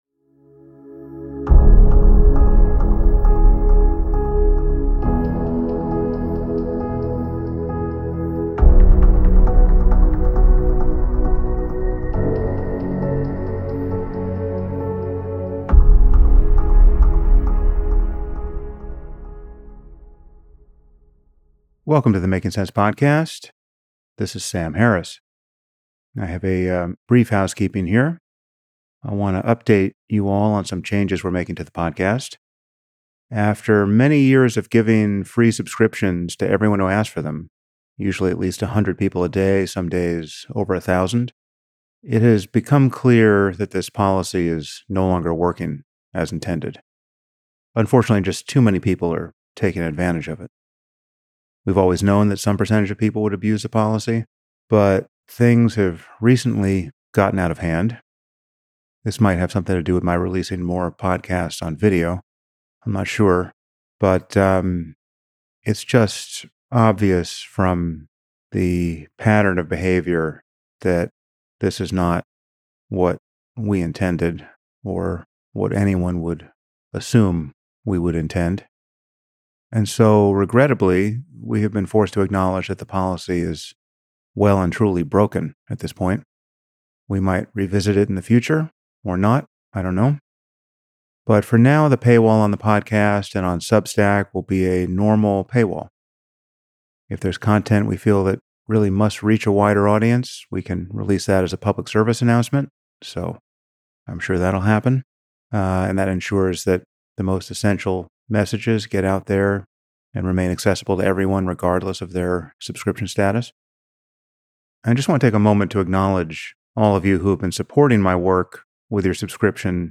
Sam Harris speaks with Jake Tapper about Jake’s new book (with Alex Thompson), Original Sin: President Biden's Decline, Its Cover-Up, and His Disastrous Choice to Run Again.